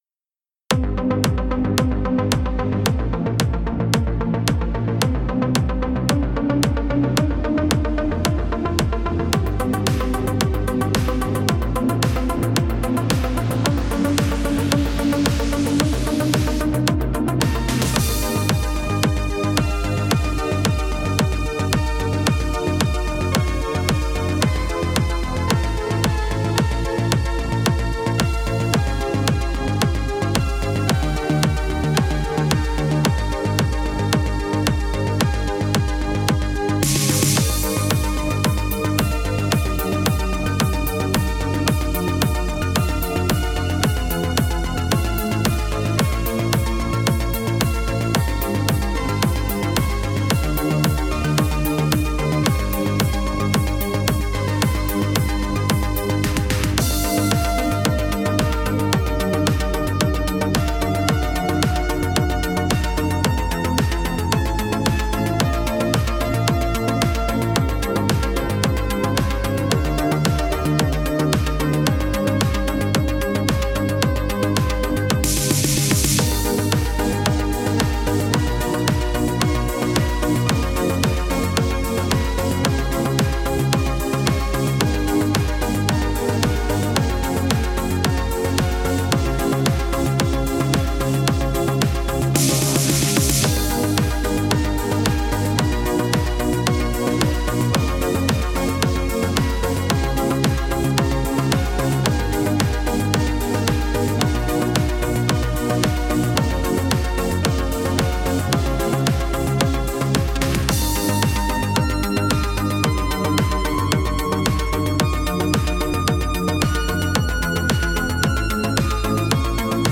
Je dis "atypique" car bien qu'il s'agisse d'une compo électro (encore une!), j'ai fait le choix de ralentir le tempo d'une part, pour ensuite travailler sévère une mélodie qui doit faire penser à des choses, sans toutefois que ce soit les dites choses...sarcastic
Ok alors , rapidos: style externe Arp Major 3  - entièrement modifié et retravaillé jusqu'à l'intro.^^happy
Divers multi-pads externes et internes.
Mixage via Audacity.
Les accords utilisés: G - D/F# - Dm/F - E - G#dim - C/G - Am - D(7) - Gsus4 - G
Puis Gm - Bb - F (6) - C